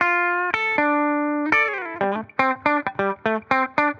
Index of /musicradar/dusty-funk-samples/Guitar/120bpm
DF_BPupTele_120-D.wav